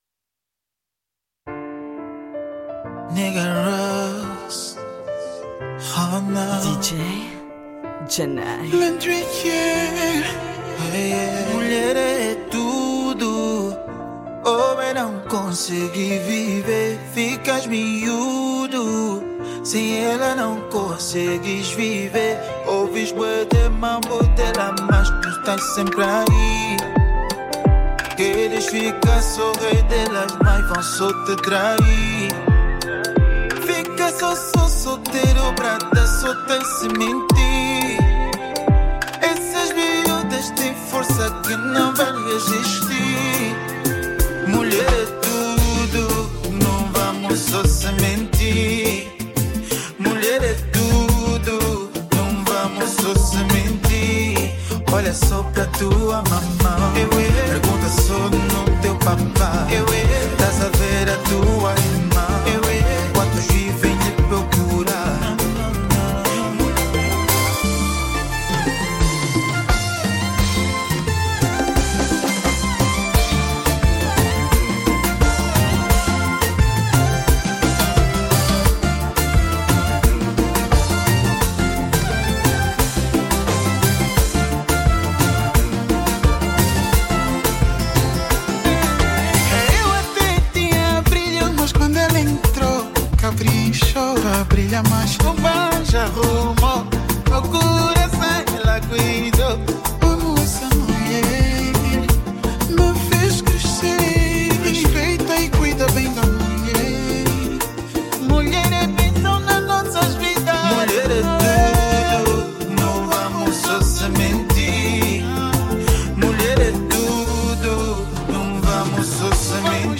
Remix Kompa